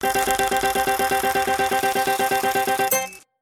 11. Приятный звук для игрока, когда начисляется потенциальный выигрыш